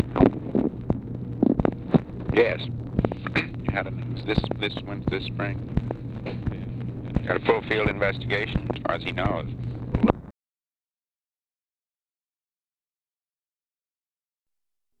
Conversation with MCGEORGE BUNDY, October 17, 1964
Secret White House Tapes